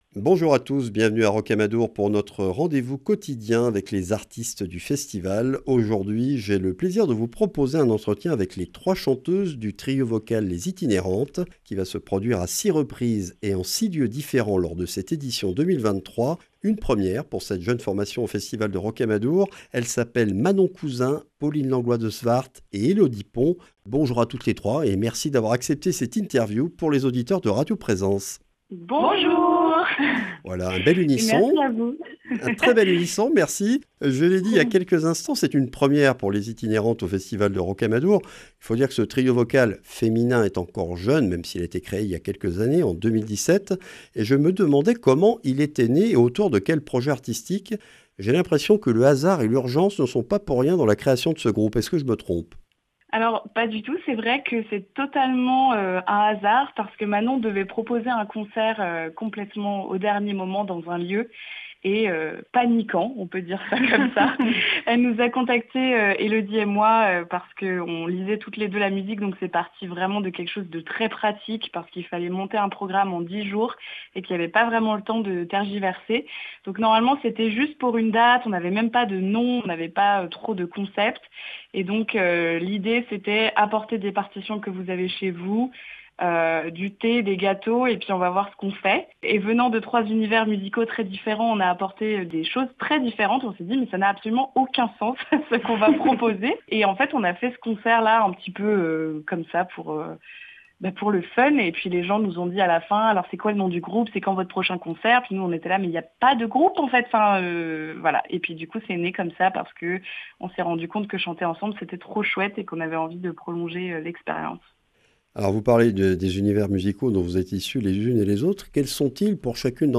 Rencontre avec trois jeunes femmes dans le vent, issues de trois univers musicaux bien distincts, qui proposent un épatant et étonnant répertoire recouvrant 9 siècles en plus de 40 langues différentes.